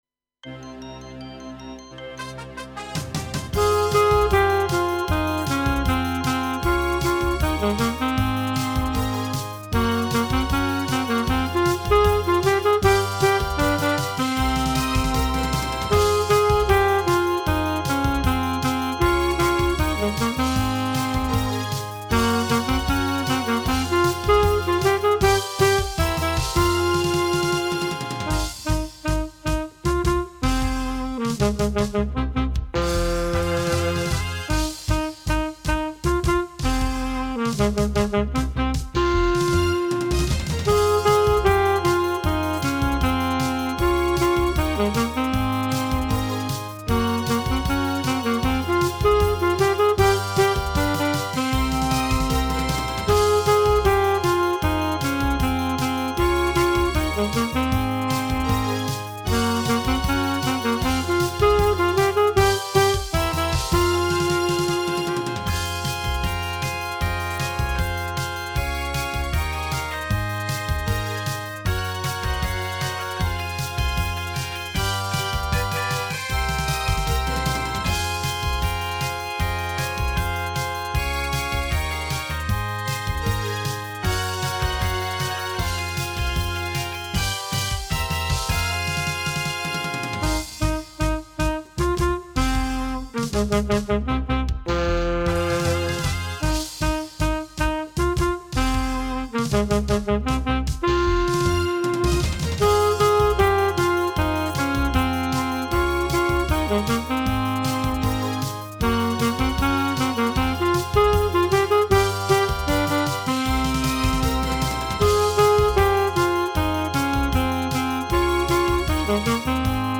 Avec saxophone alto original 158